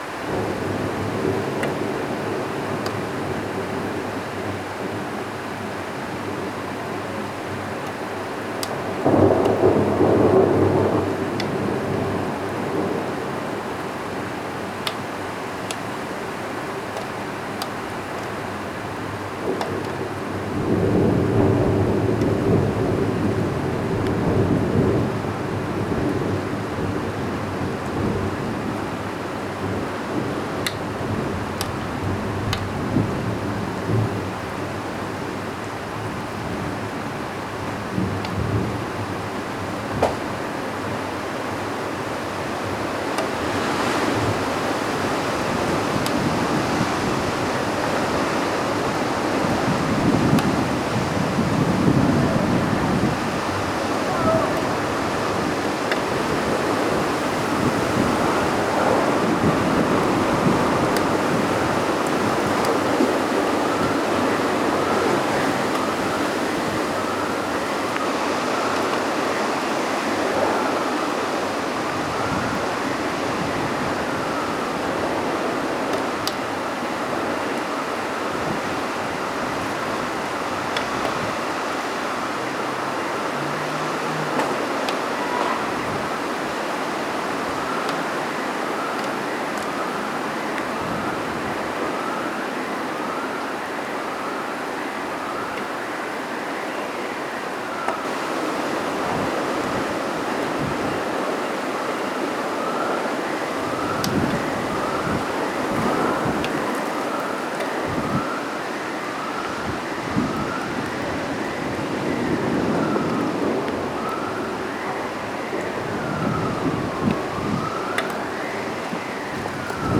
Tormenta 15 de noviembre 2025, Ciudad de Rosario - Sonidos de Rosario